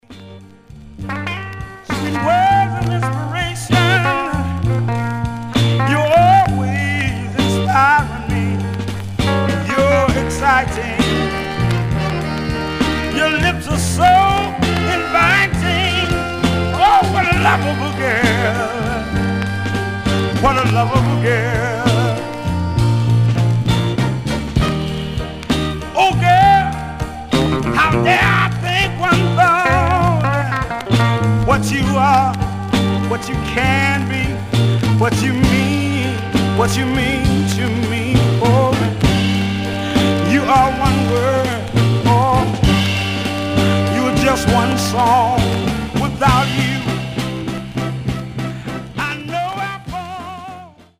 Mono
Soul